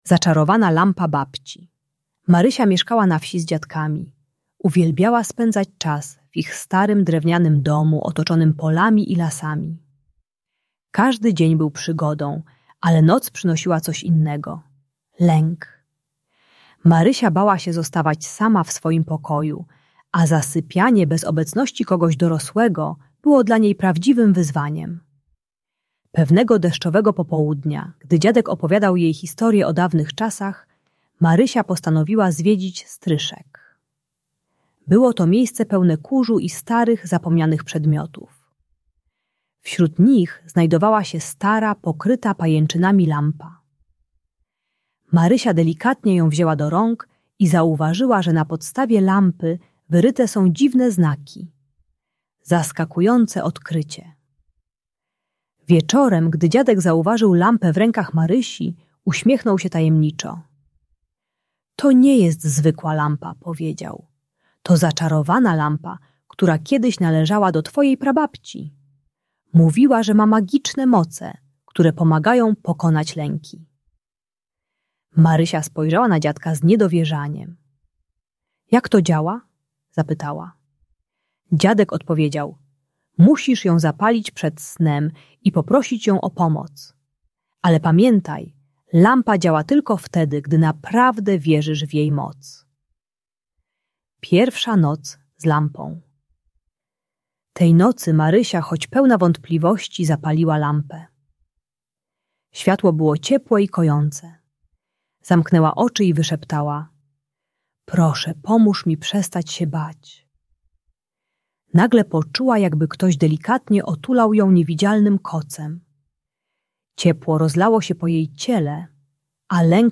Zaczarowana lampa Babci - Lęk wycofanie | Audiobajka